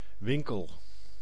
Ääntäminen
Île-de-France, France: IPA: [œ̃ ma.ɡa.zɛ̃]